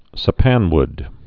(sə-pănwd, săpăn-, -ən-)